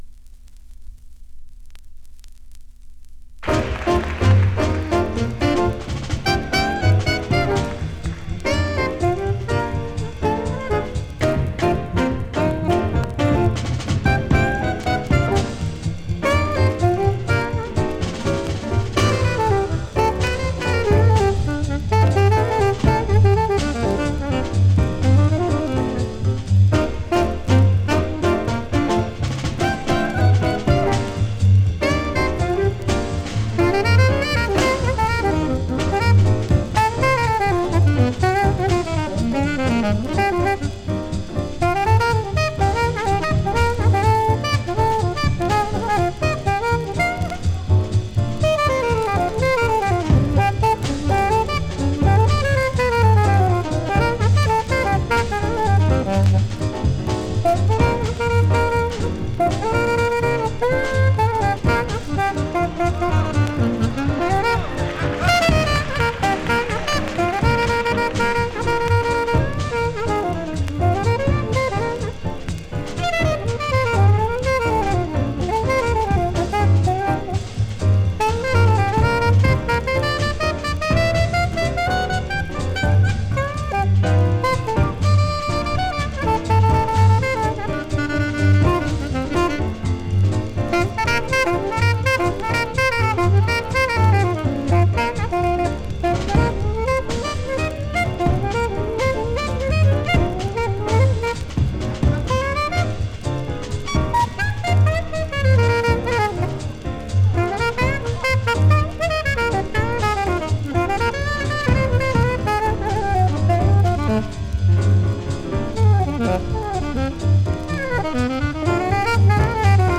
Recorded:  2 March, 1953 at Oberlin College, Oberlin, Ohio
Alto Sax
Piano
Bass
Drums